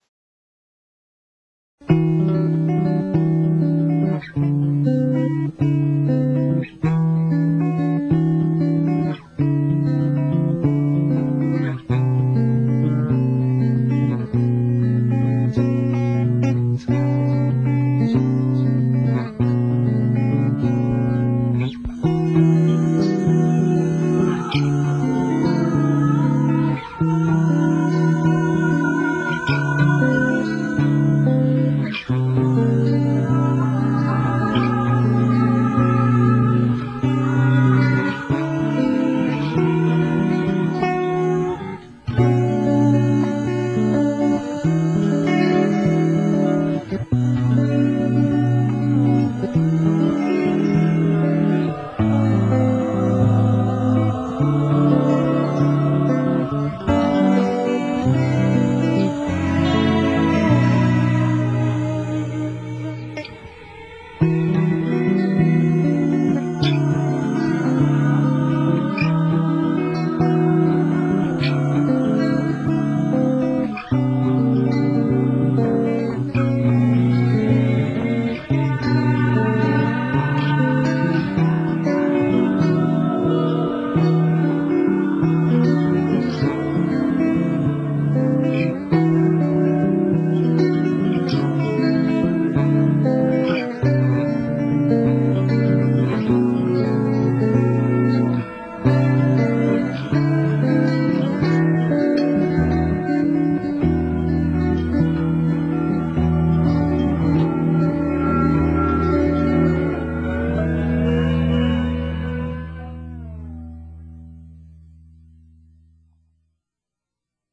m9のギターの響きだけでなんとなく録音したものに、中古レコード屋で買ってきたバリの音楽というLPを重ね、しかも、LPの回転数を手で弄って妙な風にしてみました。